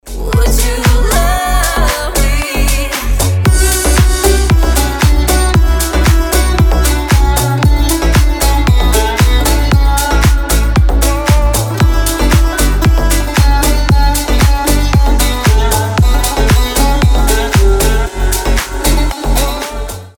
• Качество: 320, Stereo
Club House